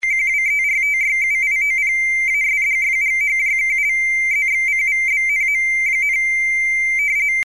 * RTTY – emisja cyfrowa wywodząca się z dalekopisu, na której amatorzy prowadzą na falach krótkich łączności przy użyciu komputerów.
rtty.mp3